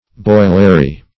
Search Result for " boilary" : The Collaborative International Dictionary of English v.0.48: Boilary \Boil"a*ry\, n. See Boilery .